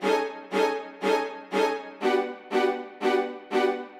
Index of /musicradar/gangster-sting-samples/120bpm Loops
GS_Viols_120-A.wav